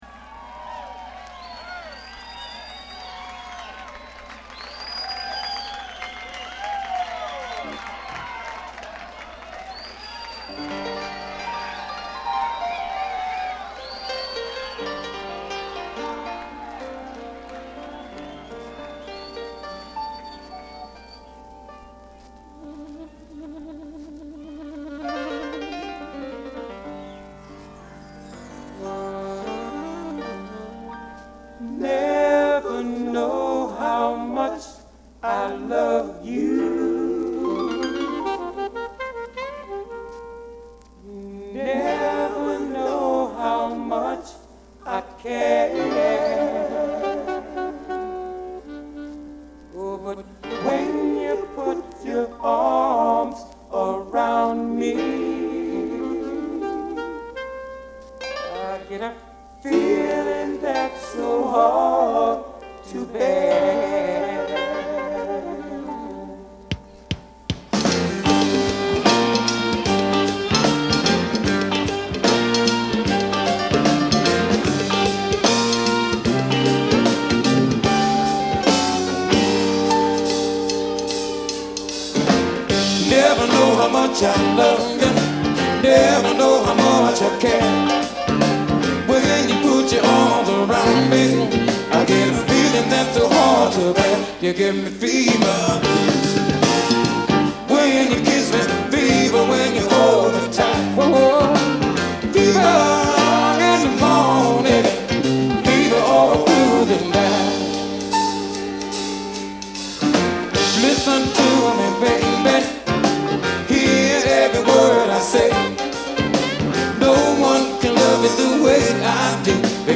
R&B / Funk / Soul / Live
ニューオーリンズR&Bとファンクのグルーヴが直に伝わる一枚。